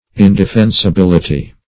Search Result for " indefensibility" : The Collaborative International Dictionary of English v.0.48: Indefensibility \In`de*fen`si*bil"i*ty\, n. The quality or state of not being defensible.
indefensibility.mp3